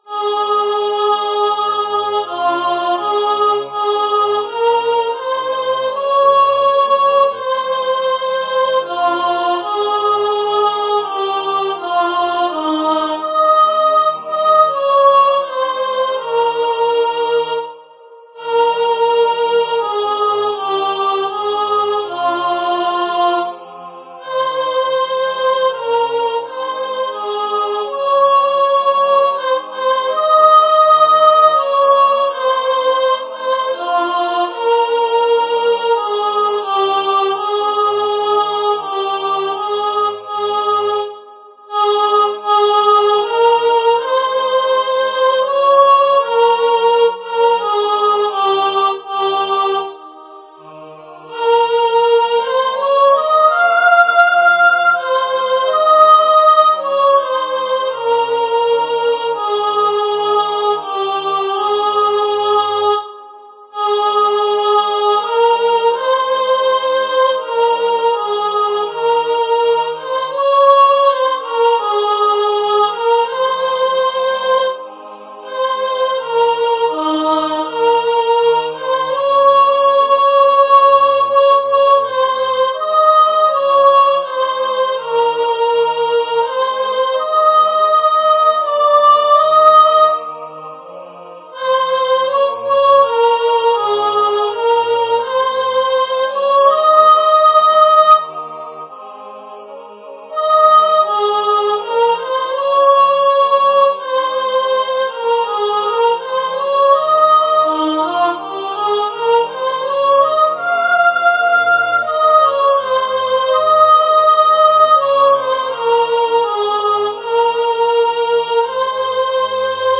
Nunc-dimittis-Gibbons-SOPRANO-1.mp3